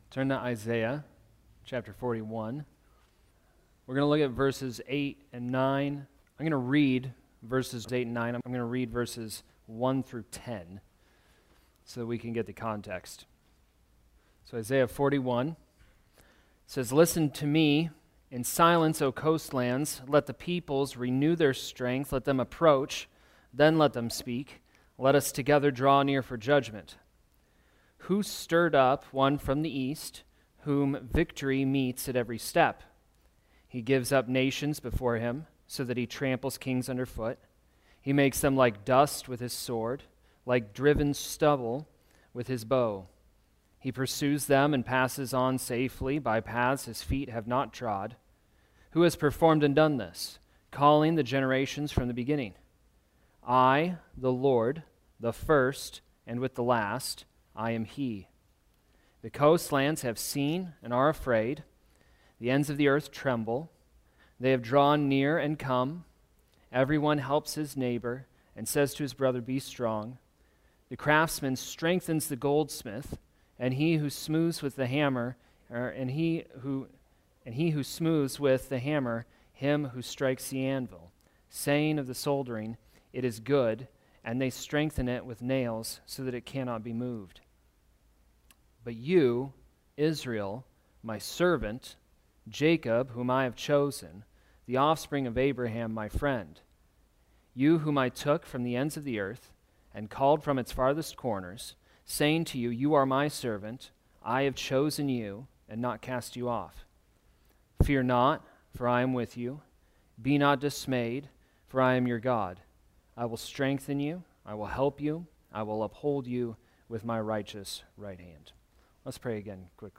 Family Night Sermons